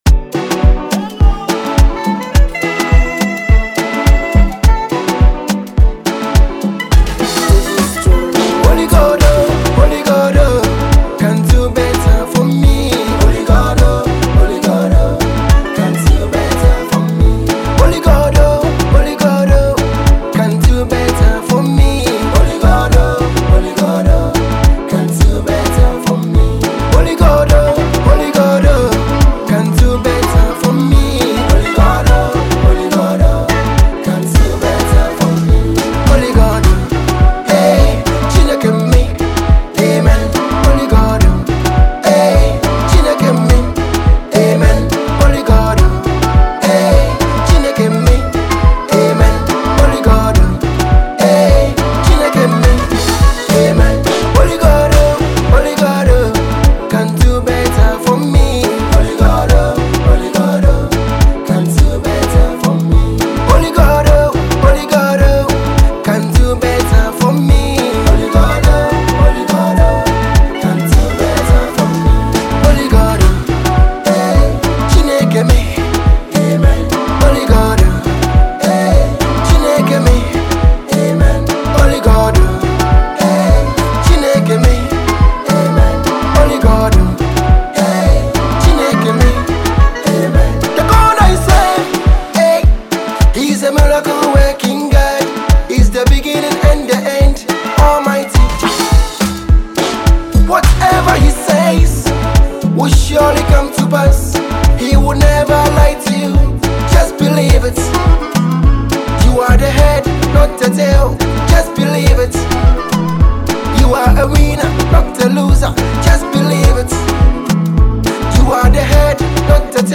celebrated Canada-based Ghanaian gospel artist
bass-rich production